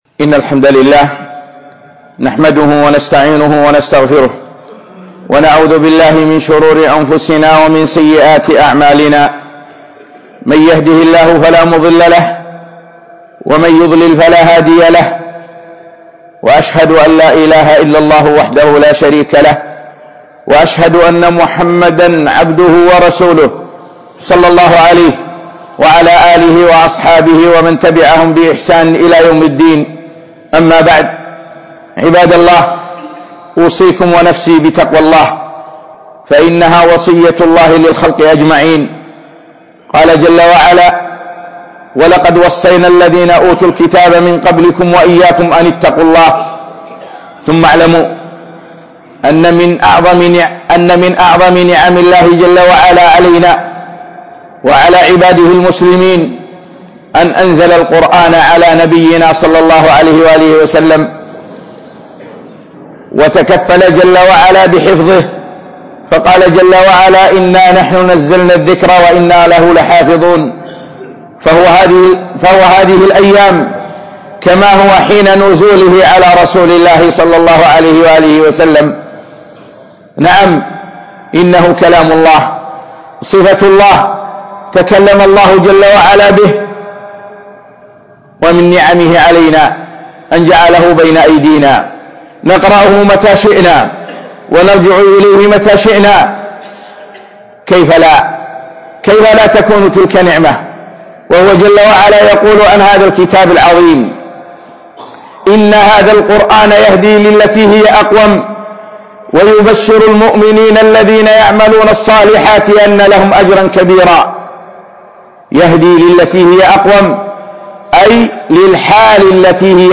خطبة - من آداب القرآن